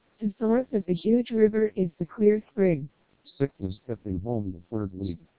Additionally, the TWELP vocoder features an NCSE (Noise Cancellation Speech Enhancement) preprocessor, which cleans the input speech signal from noise and enhances speech quality.
Below, you can listen to a short fragment of heavily noisy English speech after passing through MELPe and TWELP vocoders, with NPP (Noise Pre-Processor) and NCSE disabled and enabled, respectively.
(SNR=10dB)  MELPe
twelp700_ae_short_snr10db_ncse.wav